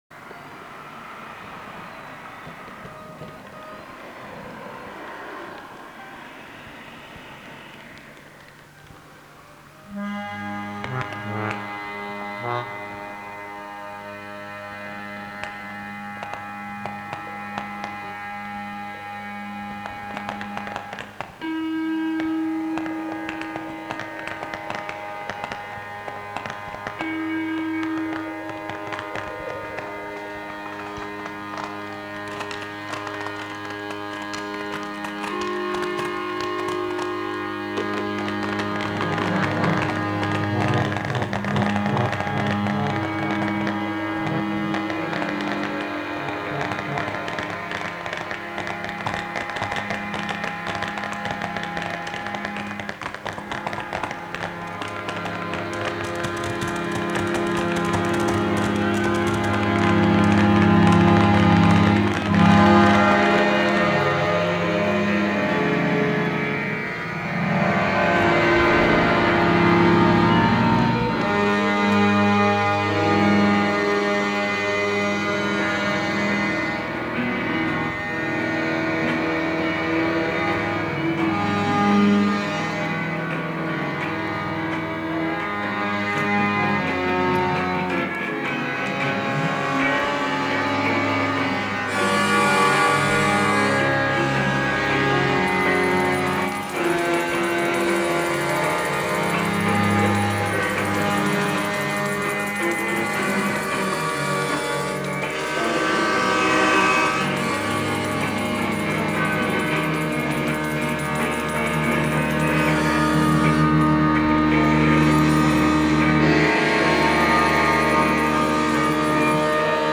Si l’improvisation resta la règle pour la série instrumentale suivante, enregistrée en deux séances à Bazemont, campagne des environs de Paris, le matériel fut plus performant : 2 micros, un magnétophone à bande 2×2 pistes et des instruments divers.